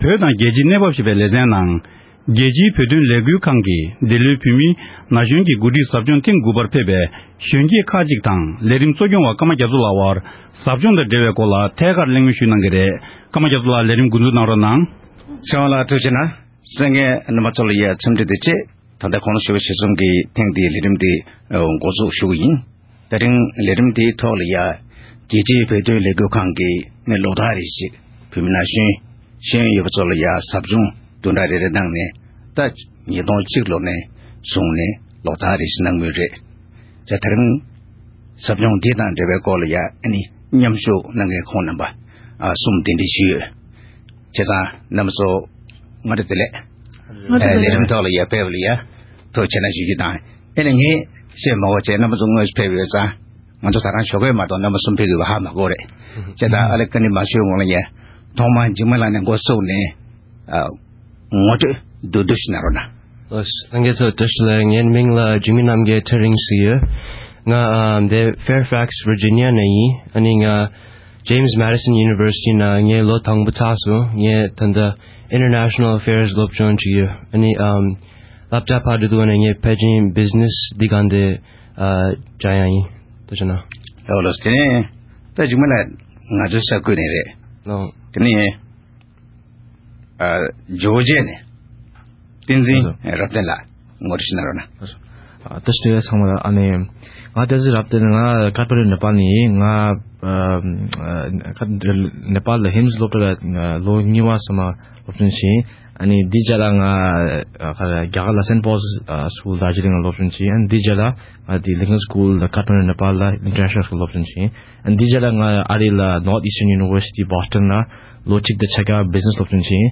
ཟབ་སྦྱོང་ནང་ཡོད་པའི་སློབ་ཕྲུག་ཁག་ཅིག་དང་གླེང་མོལ་ཞུས་པ་ཞིག་གསན་རོགས་གནང་།།